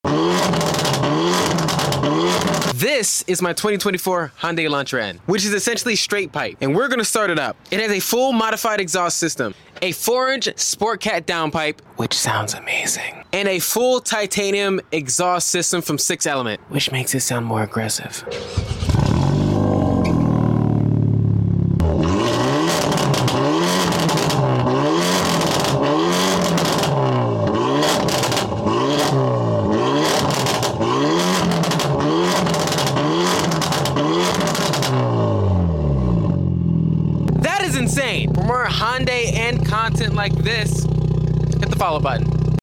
Hyundai Elantra N Exhaust Sounds